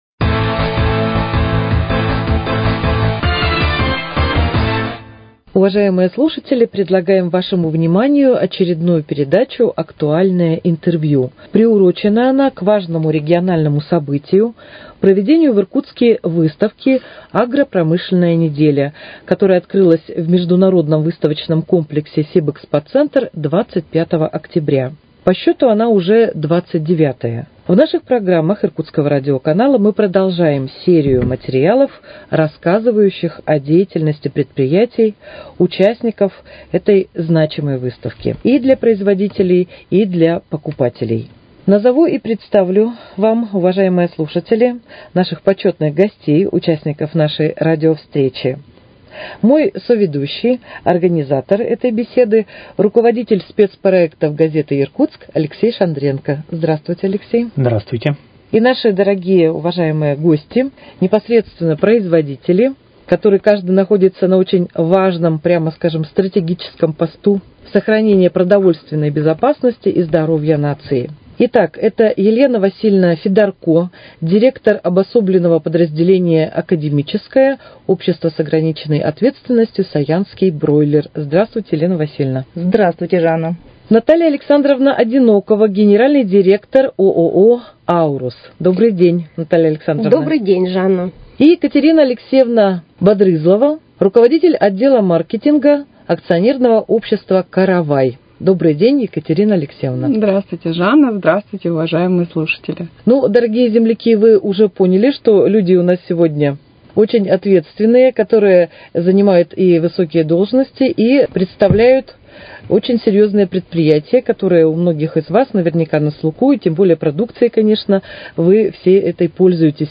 Актуальное интервью: Беседа с участниками выставки «Агропромышленная неделя»